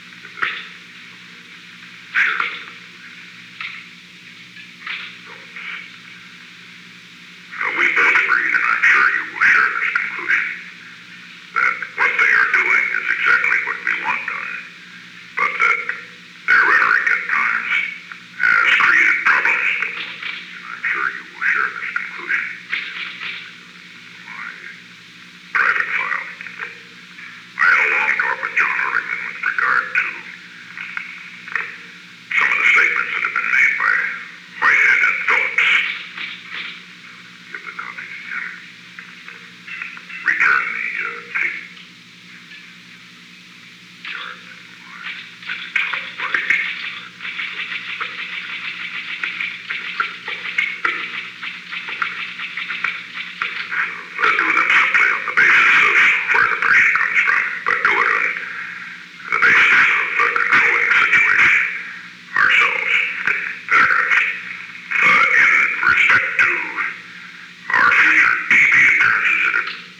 Secret White House Tapes
Location: Oval Office
The President played portions of a previously recorded dictabelt tape.
President’s conversation with John D. Ehrlichman